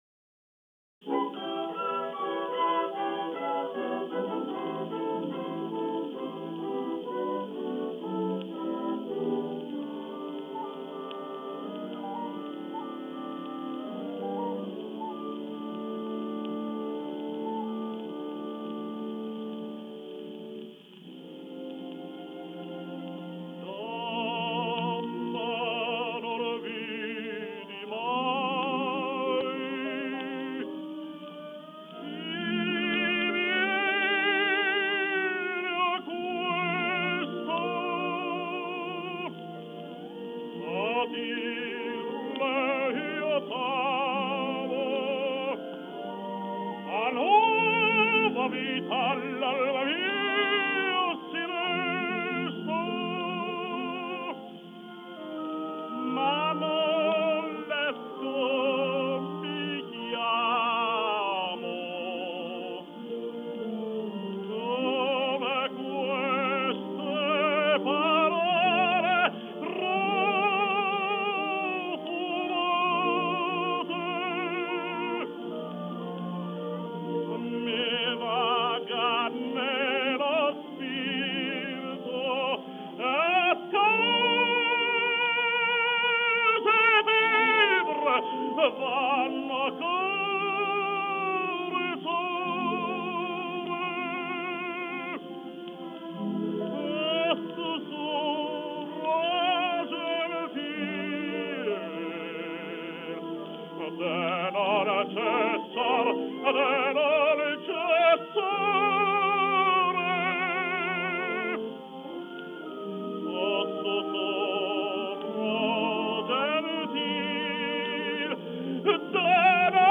Жанр: Vocal